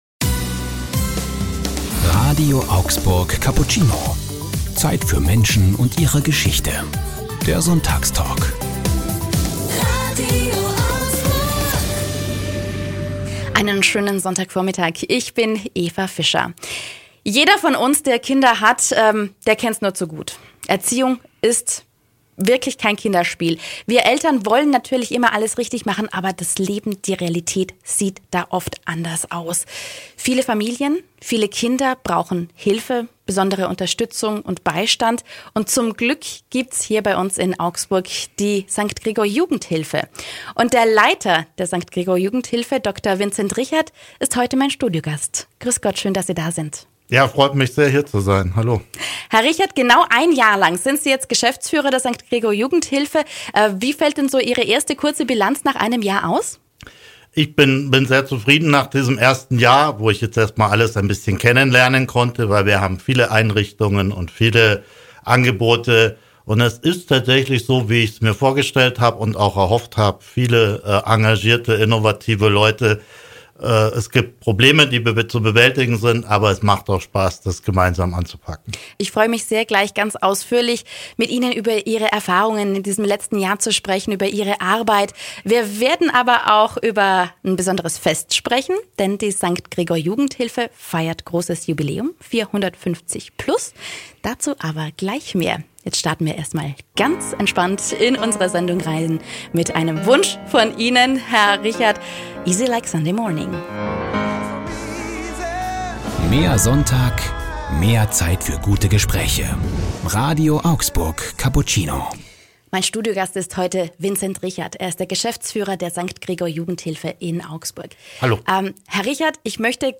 Sonntagstalk